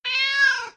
Scp_cat_voice_b_3.mp3